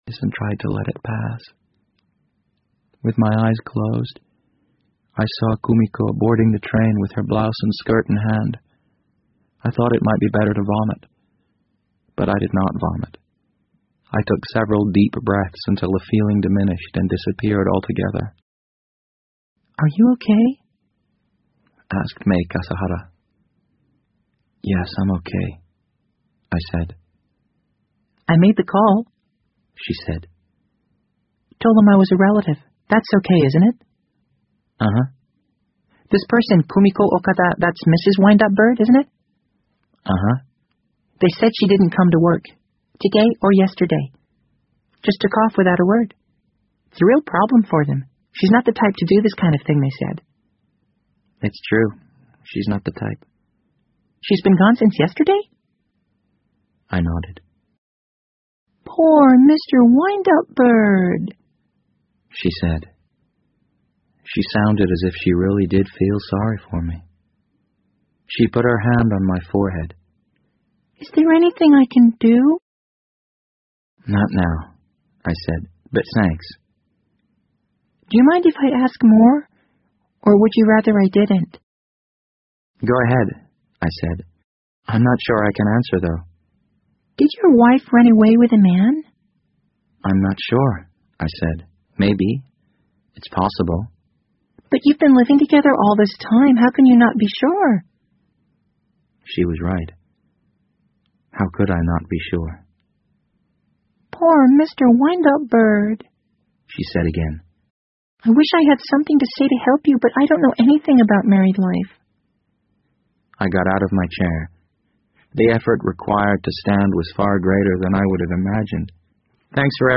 BBC英文广播剧在线听 The Wind Up Bird 005 - 13 听力文件下载—在线英语听力室